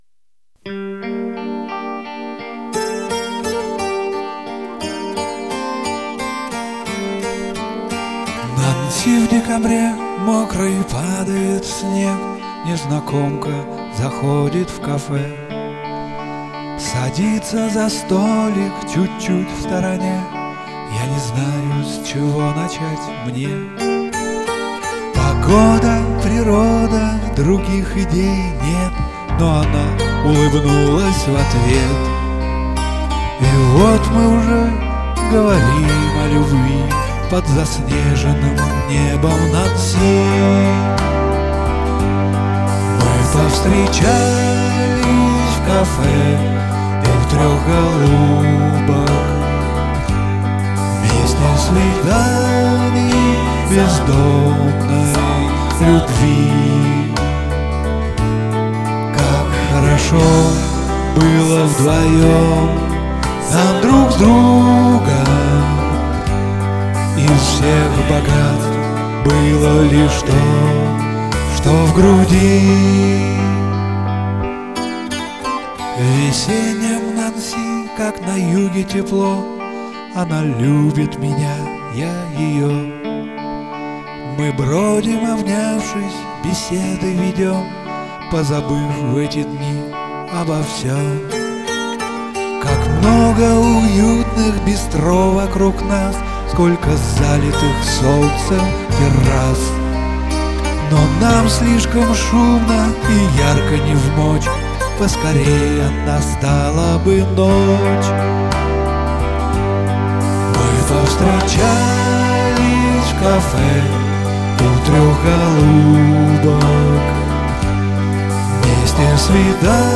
красивый голос, интимный такой, теплый, близкий
"В" в некоторых местах звучит как "Ф".